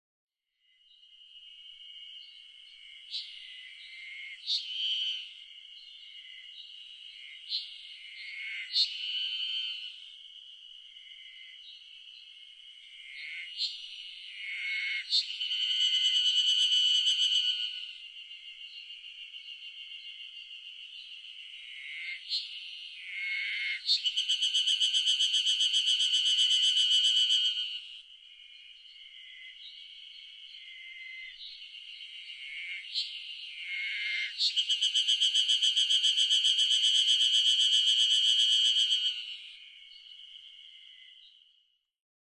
エゾハルゼミ　Terpnosia nigricostaセミ科
日光市細尾　alt=1100m  HiFi --------------
MPEG Audio Layer3 FILE  Rec.: EDIROL R-09
Mic.: built-in Mic.